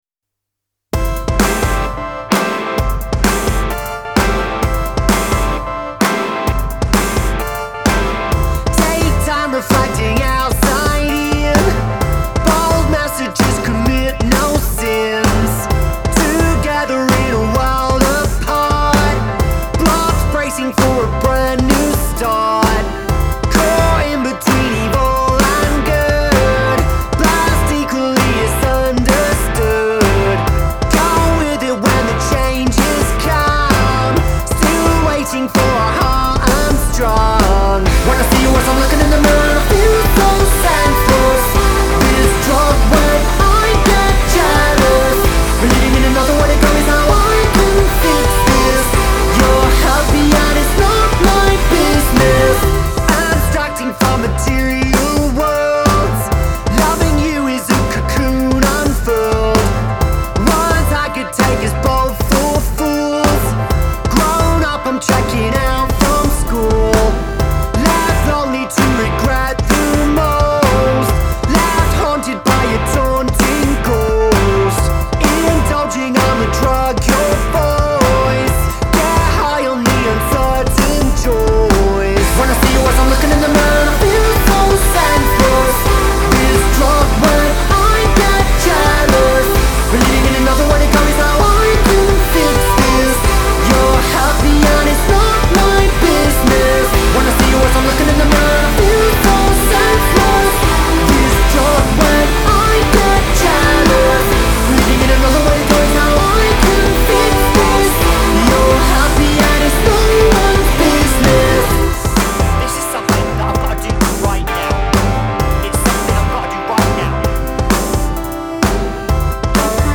Genre : Alternative, Indie